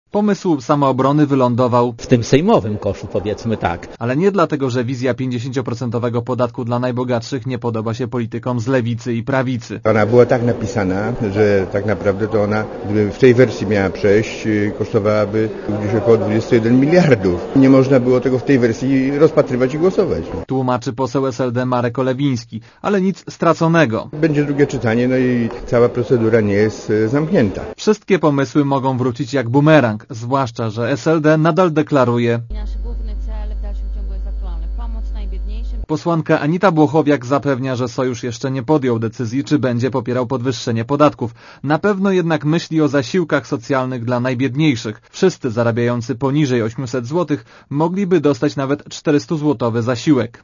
podatki-komisja_sejmowa.mp3